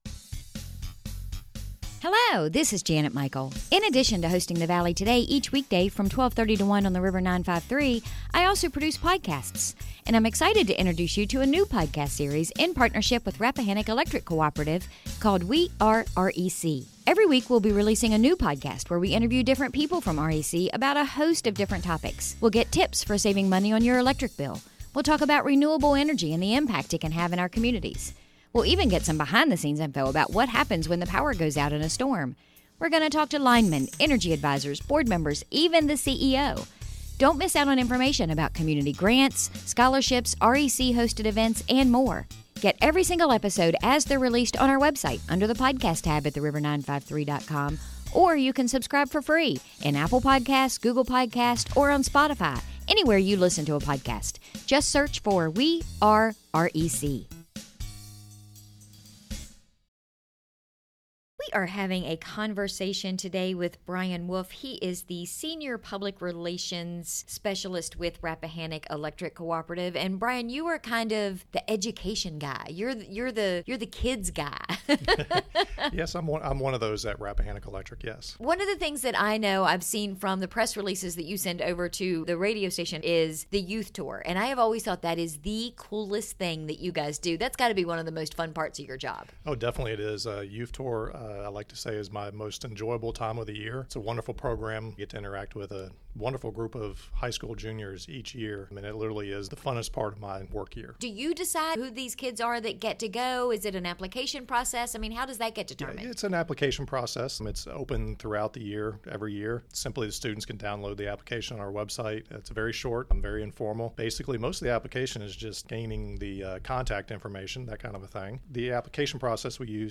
We had a conversation today